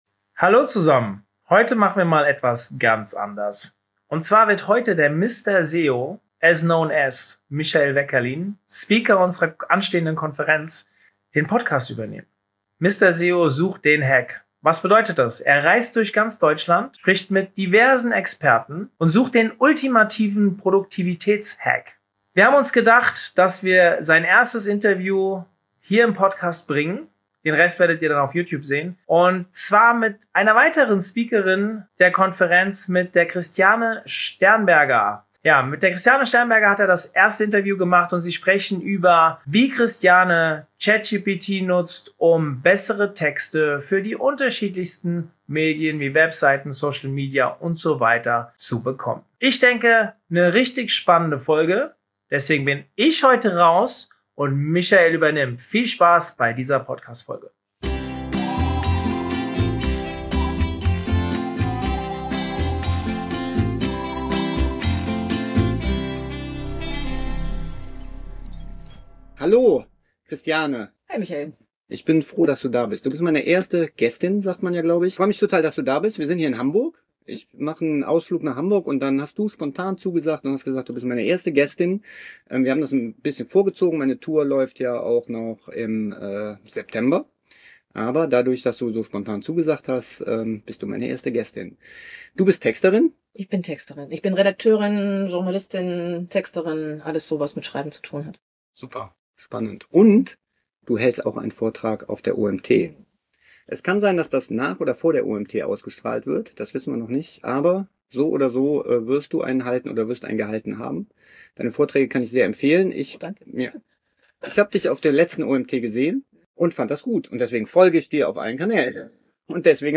Die Suche führt ihn heute zum Fischmarkt in Hamburg